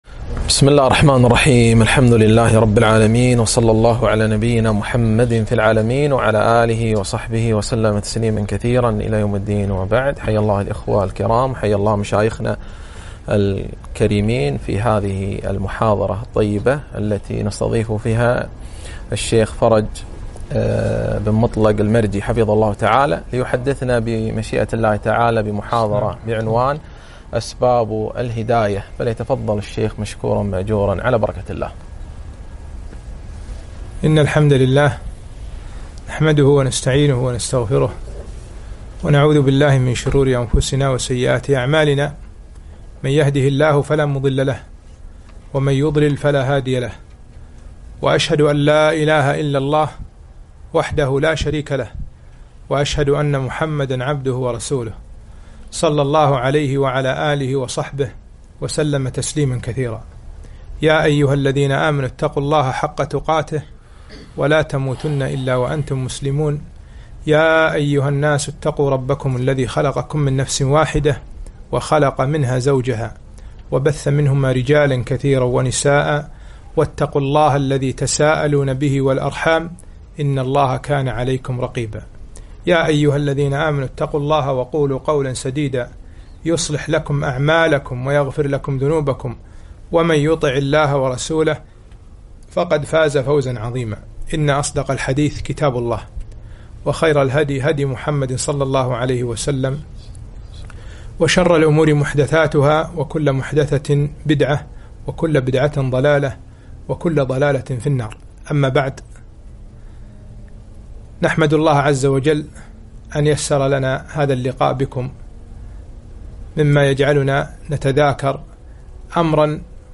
محاضرة - أسباب الهداية